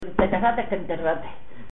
Materia / geográfico / evento: Refranes y proverbios Icono con lupa
Secciones - Biblioteca de Voces - Cultura oral